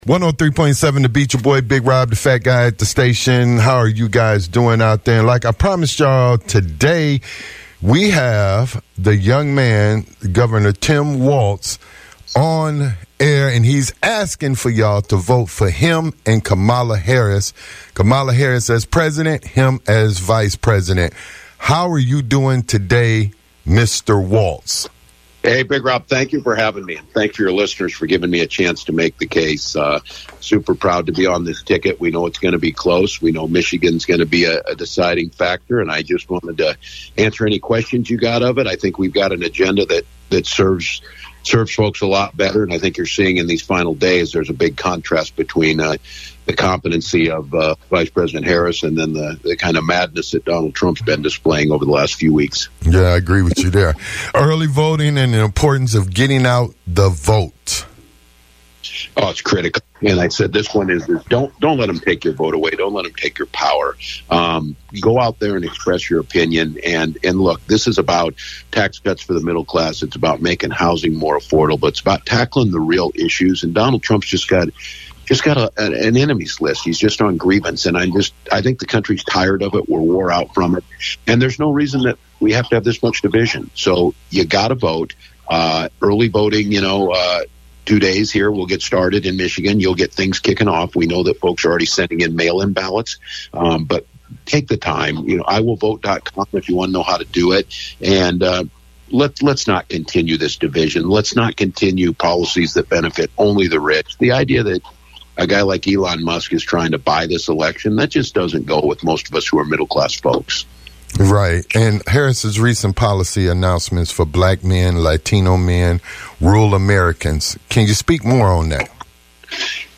Interview with VP Candidate Tim Walz
Interview with VP Candidate Tim Walz regarding the Presidential Election and more